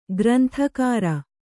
♪ granthakāra